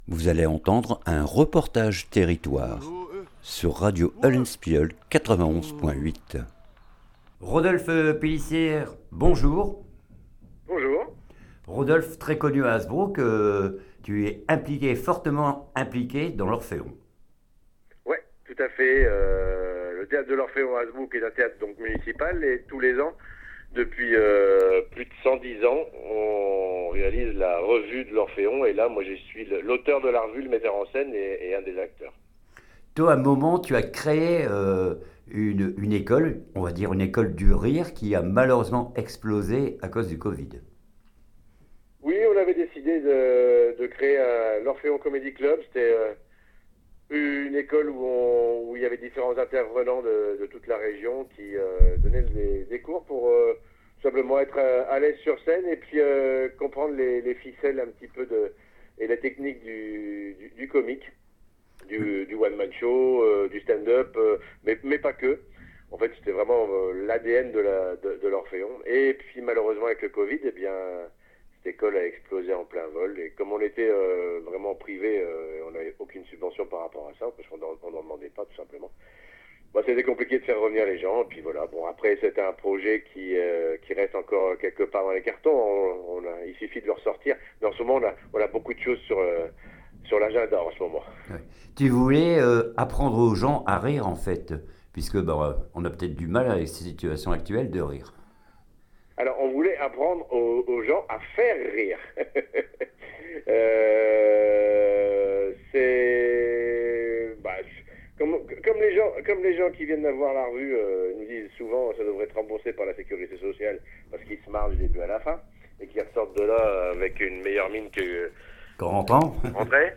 REPORTAGE TERRITOIRE VLAAMS RAP